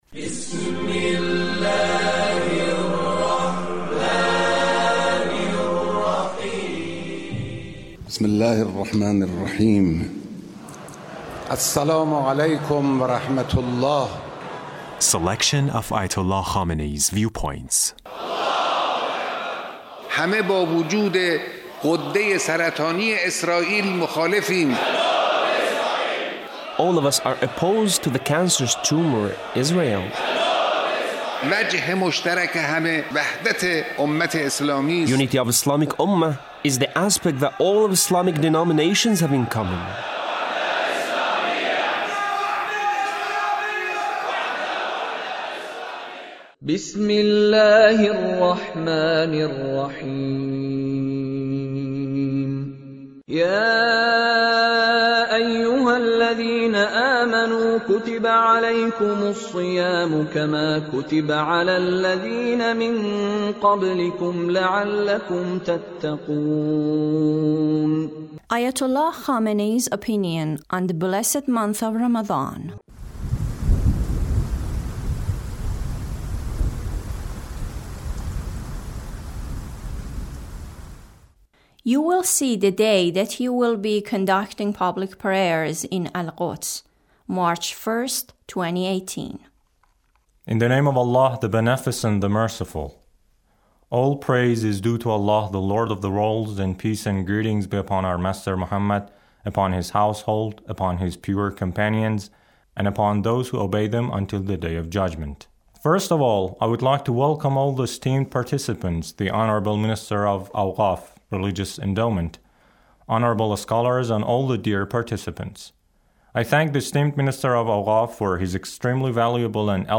Leader's speech (93)